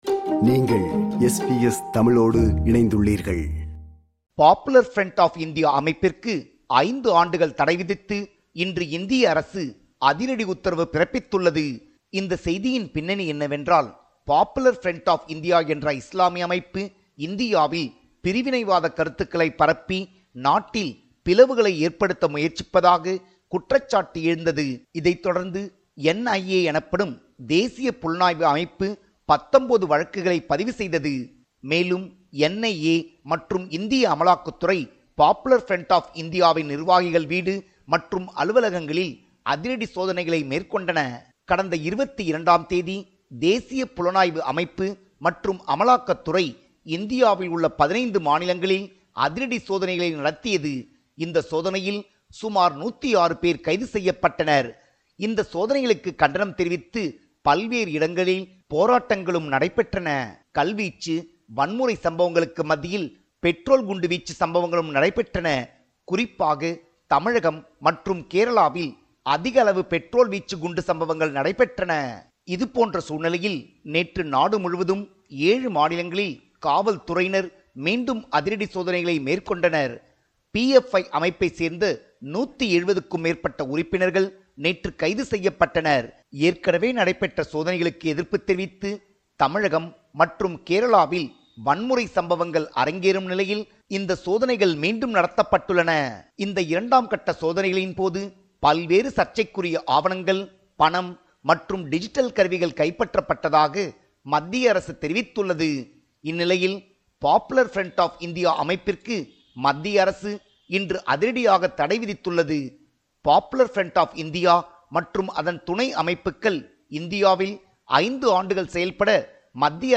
our correspondent in India, compiled a report focusing on major events/news in Tamil Nadu / India.